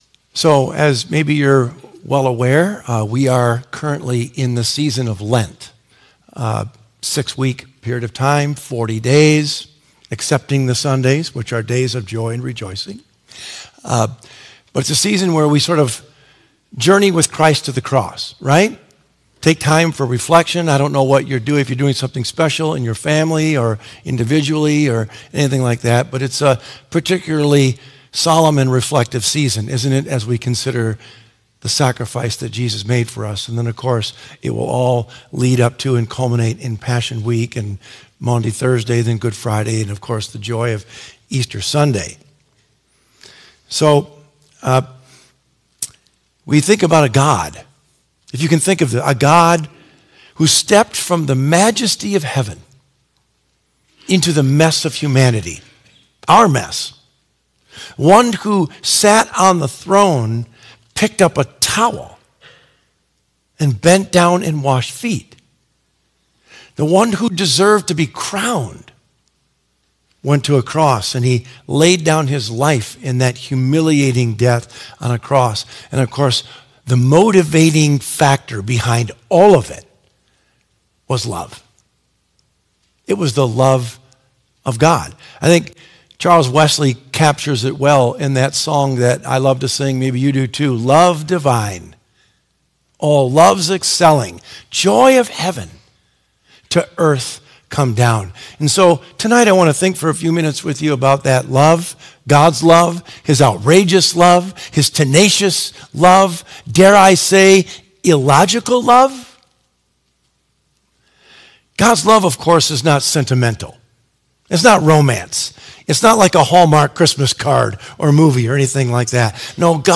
Sermon Recordings | Faith Community Christian Reformed Church
“God’s Illogical Love” March 8 2026 P.M. Service